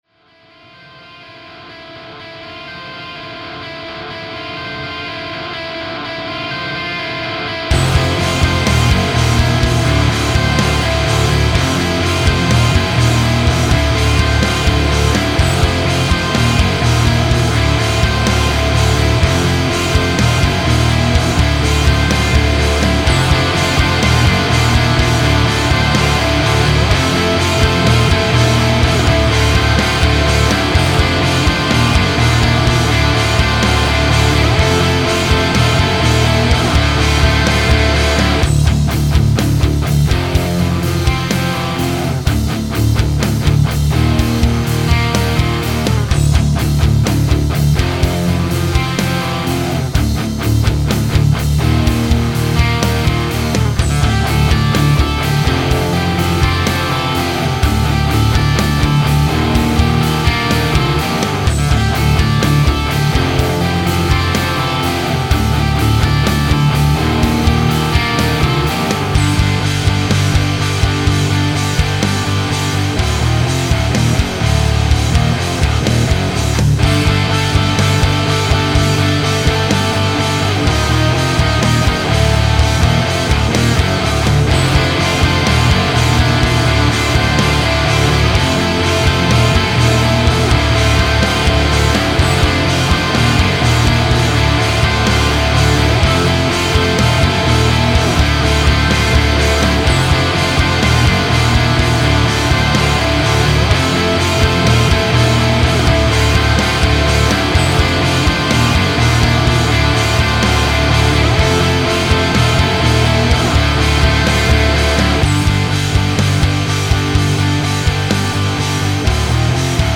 Petit sample rapide encore, mais cette fois, fait avec la M1 et une LesPaul Studio.
Réglages de la M1 : facile, tout à midi sauf le potard des aigus que j'ai mis à 11:00.
La, il y a deux pistes de gratte pour la rythmique (droite/gauche) et deux pistes de grattes au centre pour les mélodies, plus une basse, et c'est tout (capture d'ecran de la session cubase) !